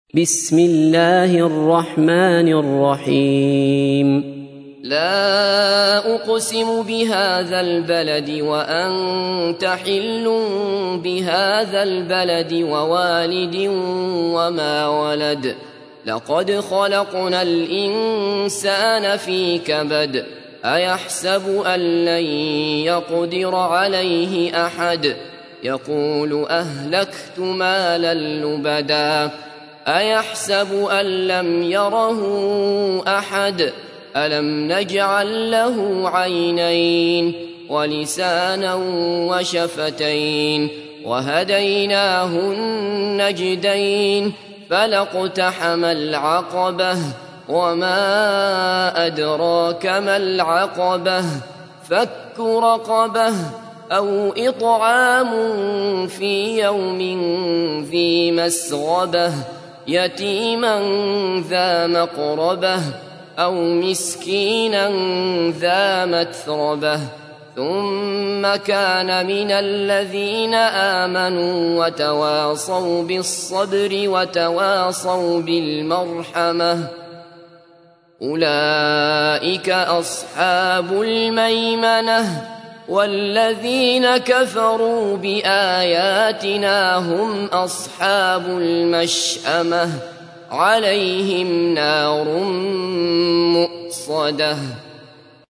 تحميل : 90. سورة البلد / القارئ عبد الله بصفر / القرآن الكريم / موقع يا حسين